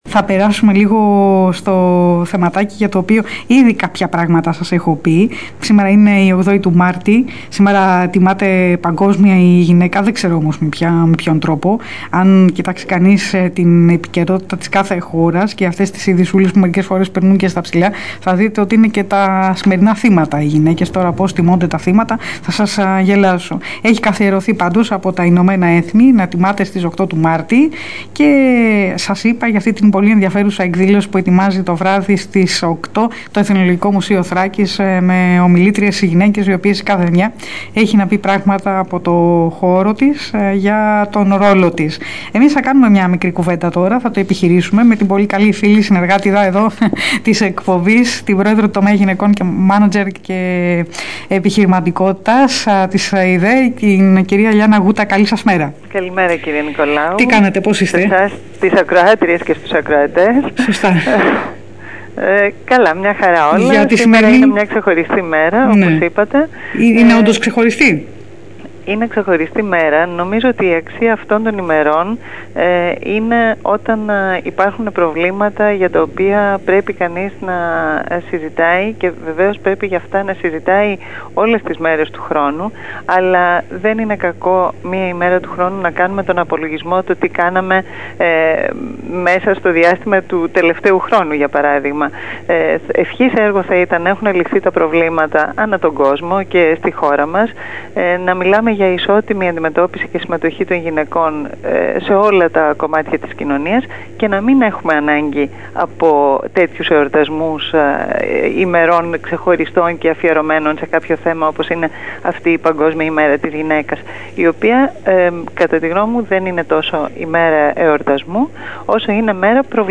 Ραδιοφωνική συνέντευξη για την Ημέρα της Γυναίκας